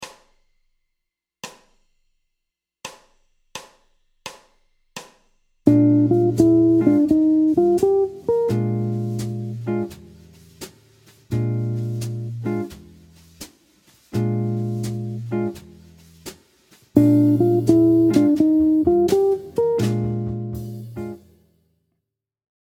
Lick Jazz-Blues # 8
Lick sur le degré I7 avec encadrement de la Tierce.